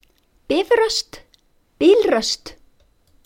Listen to pronunciation: Valhöll, Hel Helia, Andhrímnir Eldhrímnir Sæhrímnir, Bifröst Bilröst, nýsa niður